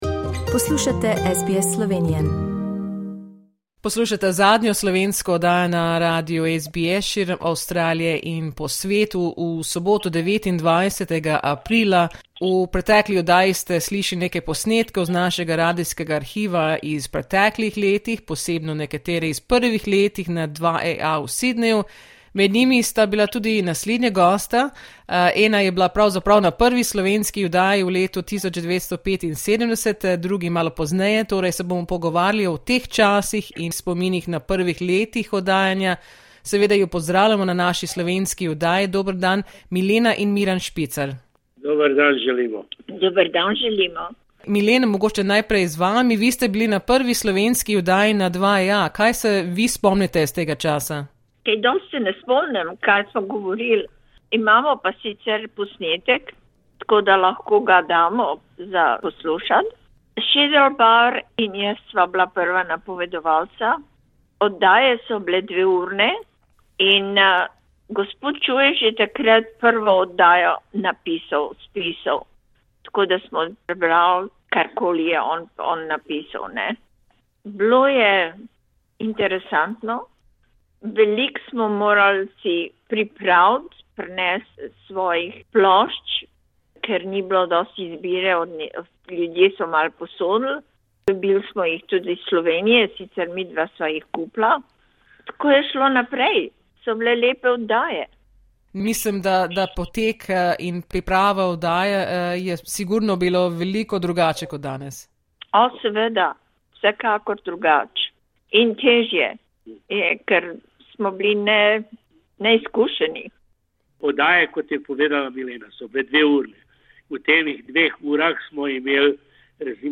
Pogovarjala sta se o teh časih in obujala spomine na prvem letu oddajanja na Radio 2EA v Sydneyu.